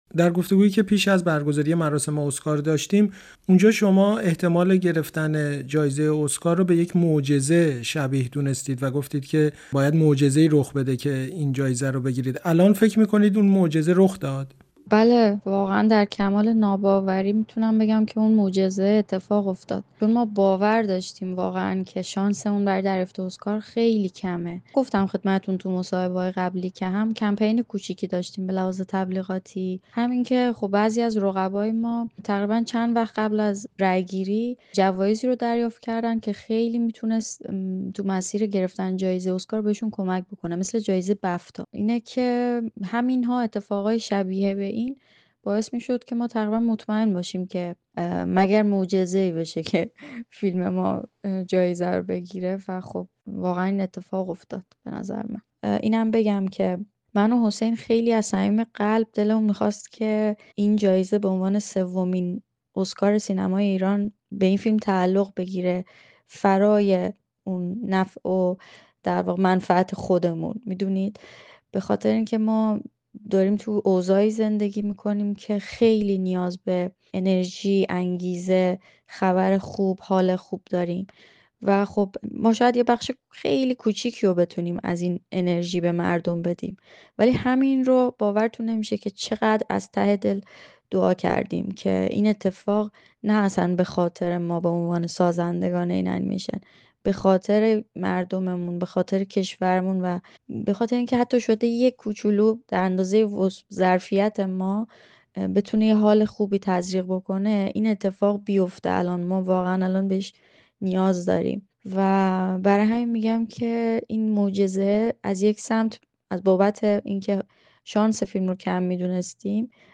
شیرین سوهانی در گفت‌وگو با رادیوفردا پس از دریافت این جایزه، توضیحاتی دربارۀ صحبت‌هایشان هنگام دریافت اسکار و حاشیه‌های بعد از آن و نیز نگاه‌شان به تأثیر این جایزه داده است.